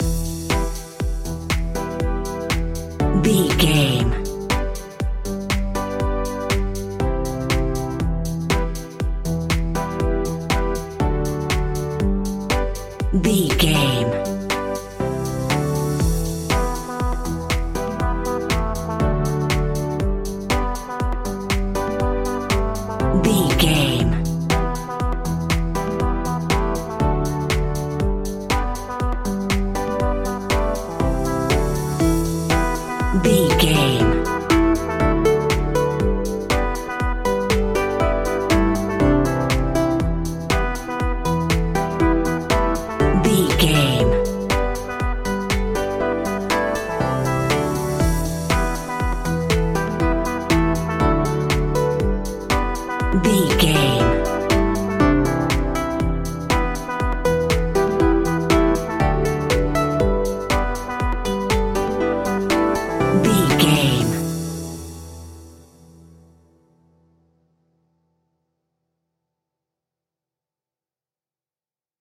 Aeolian/Minor
calm
joyful
synthesiser
drum machine
electric piano
acoustic guitar
electronic
synth leads
synth bass